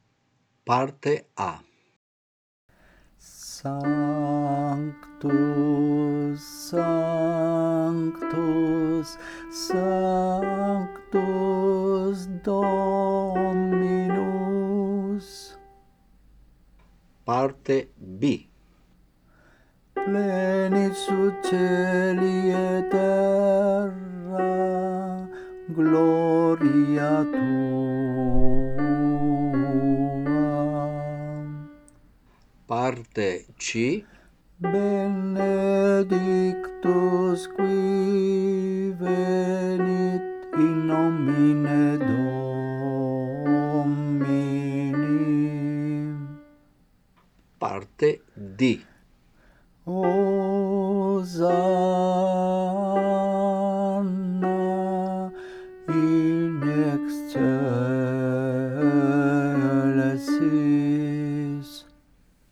Parte contralti